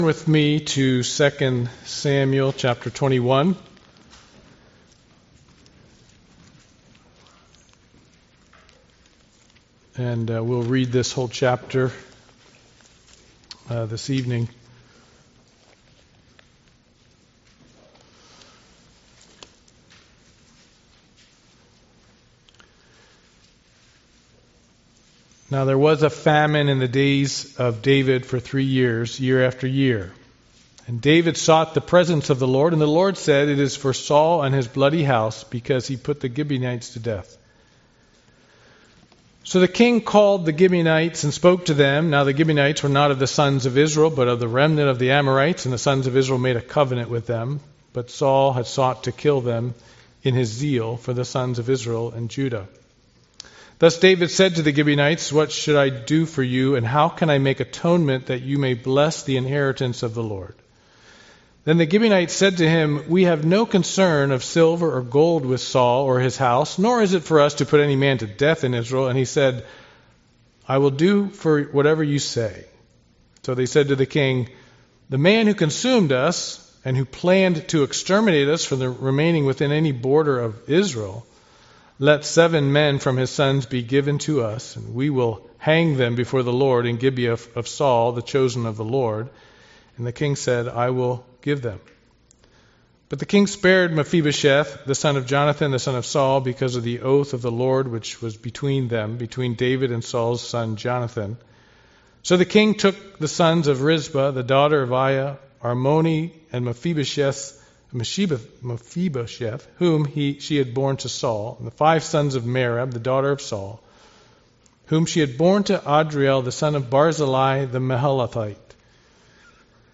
2 Samuel Passage: 2 Samuel 21 Service Type: Sunday Evening Worship « Escaping the Corruption of the World 6 Sorrento